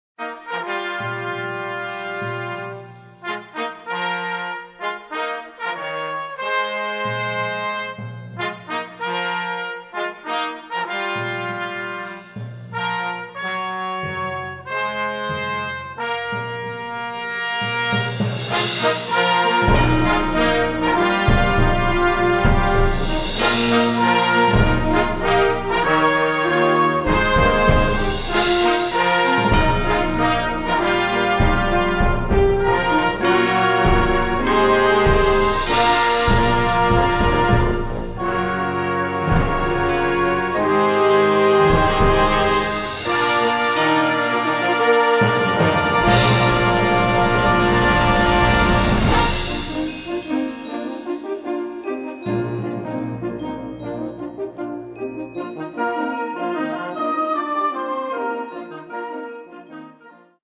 Besetzung: Blasorchester
Following the introductory fanfare